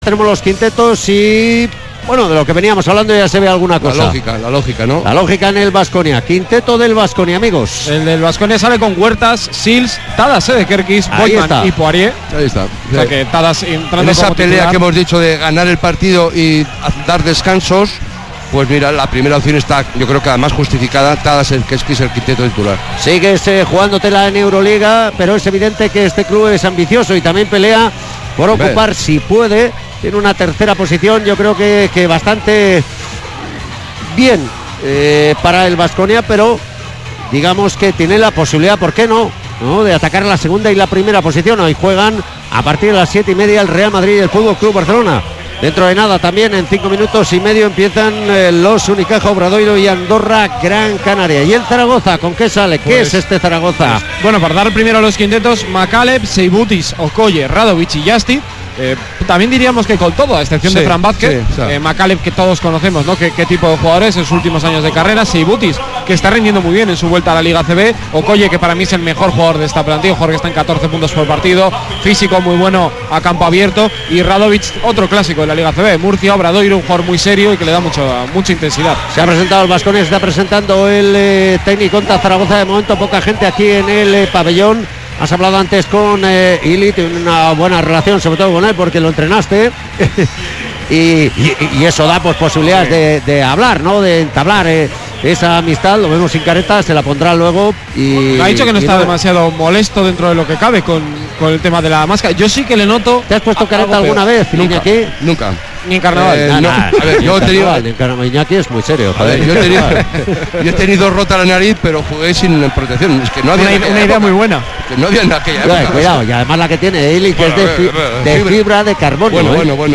Techniconta Zaragoza-Kirolbet Baskonia jornada 24 ACB 2018-19 retransmisión Radio Vitoria